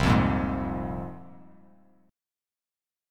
C7sus4#5 chord